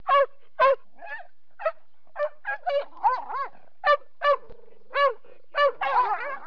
جلوه های صوتی
دانلود صدای حیوانات جنگلی 110 از ساعد نیوز با لینک مستقیم و کیفیت بالا